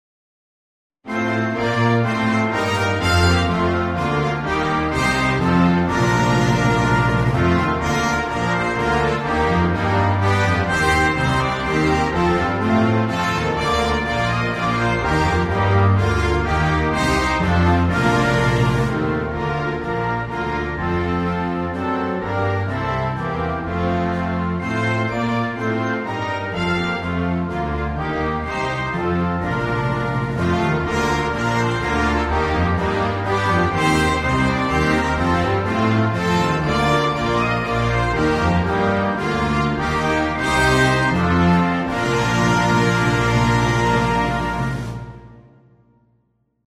Shorter but full version for Concert Band and Orchestra
The MP# was recorded with NotePerformer 3.
(1 Verse and Chorus)